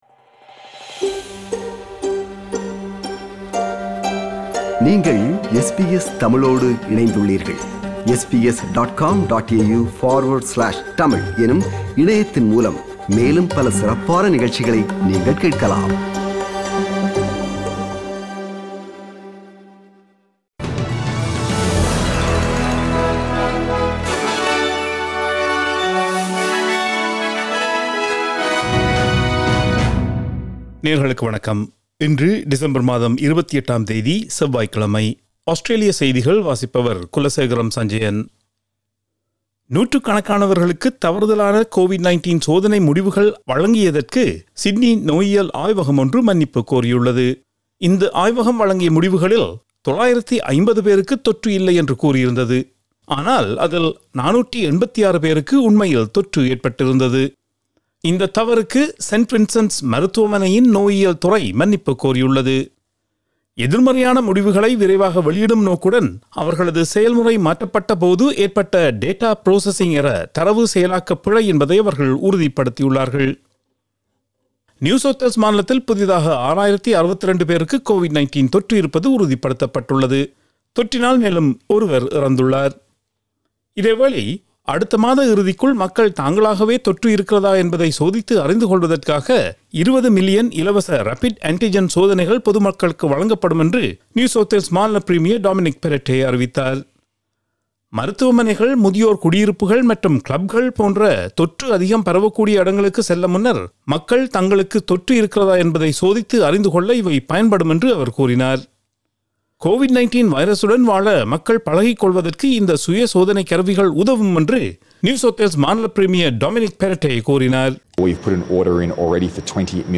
Australian news bulletin for Tuesday 28 December 2021.